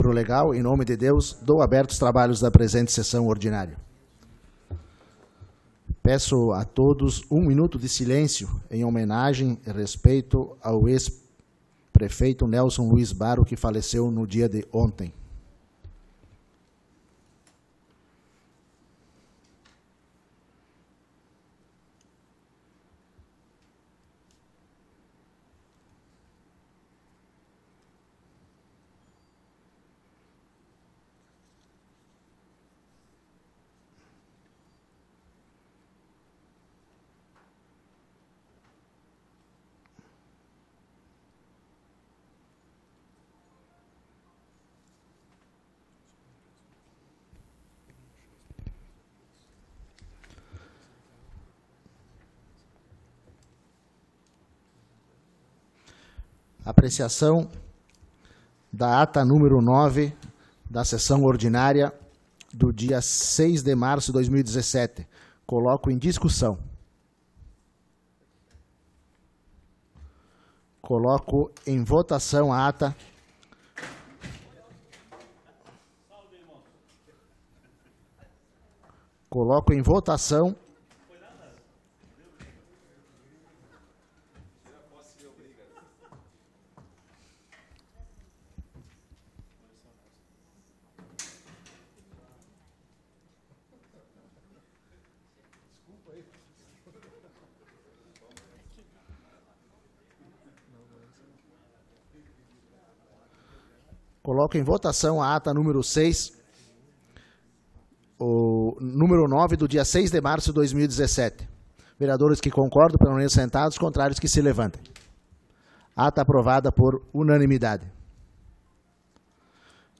Sessão Ordinária do dia 20 de Março de 2017